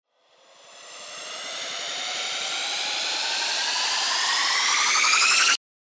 FX-1485-RISER
FX-1485-RISER.mp3